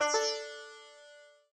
sitar_da.ogg